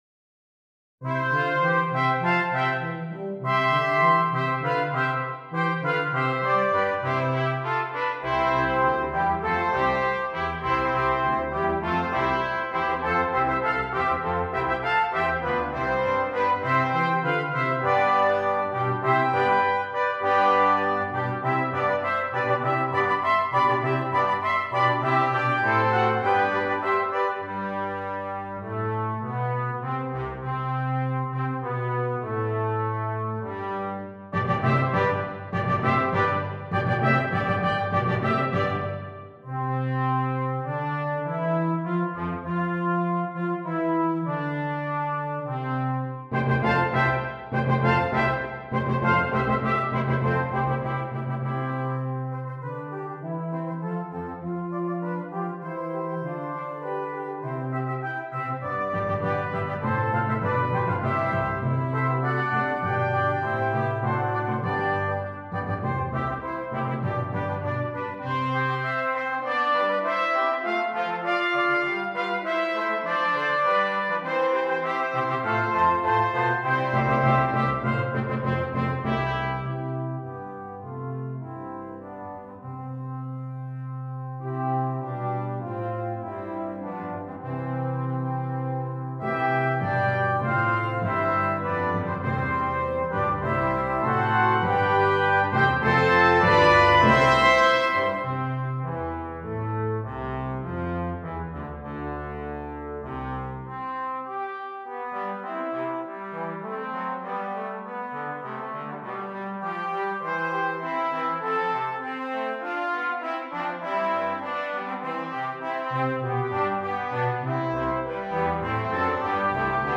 Brass Choir (3.2.2.0.1.timp)